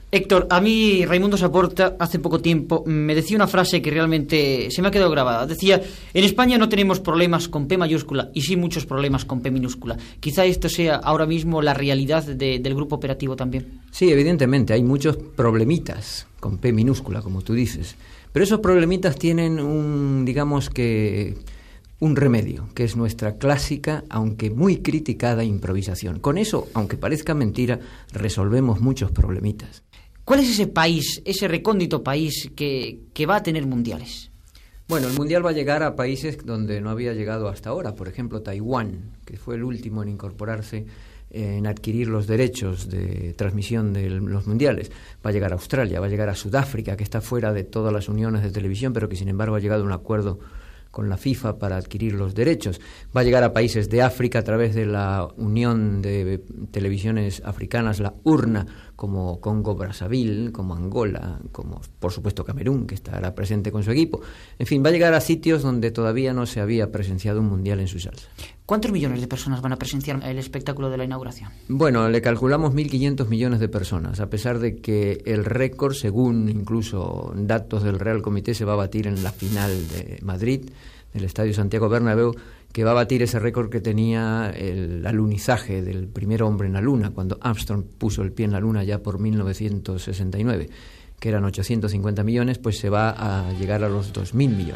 Esportiu
Fragment extret del programa "Audios para recordar" de Radio 5 emès el 11 de juny del 2018.